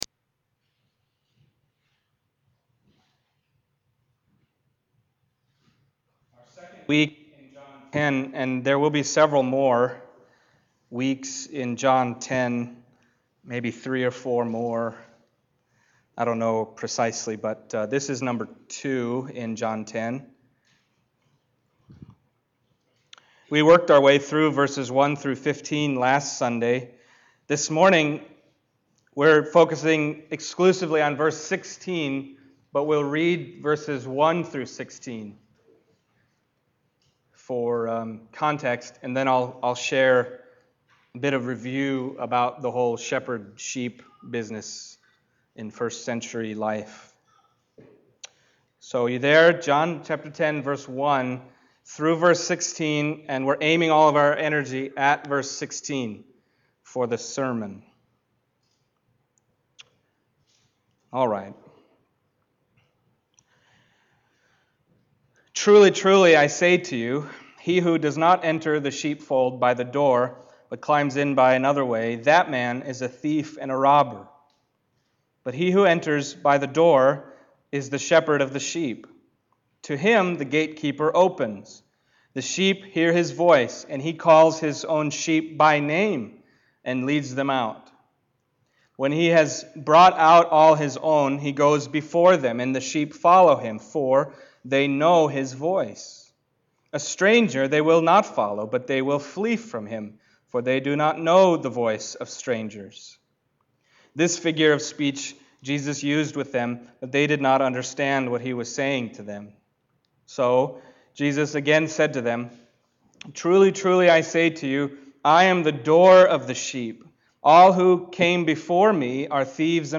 John Passage: John 10:1-16 Service Type: Sunday Morning John 10:1-16 « I Once Was Blind